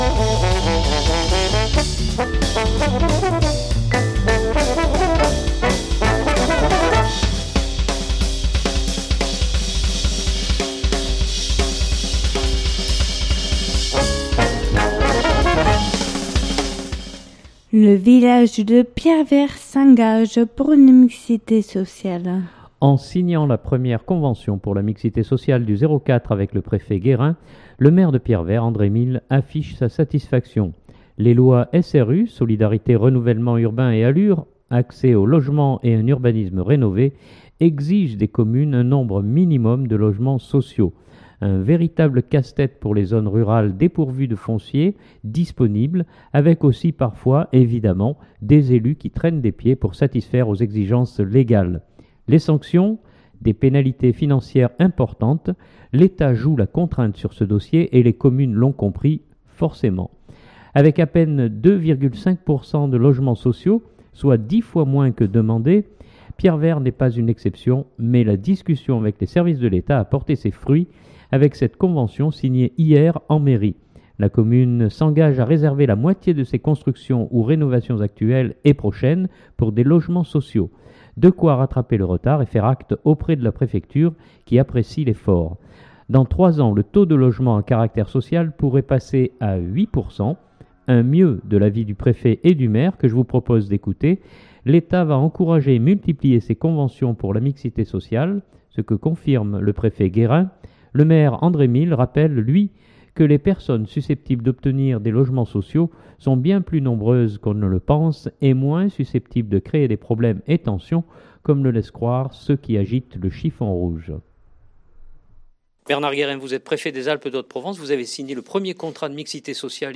Un mieux, de l’avis du préfet et du maire que je vous propose d’écouter. L’Etat va encourager et multiplier ces conventions pour la mixité sociale, ce que confirme le préfet Guérin.